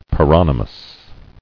[pa·ron·y·mous]